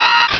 Cri de Chenipotte dans Pokémon Rubis et Saphir.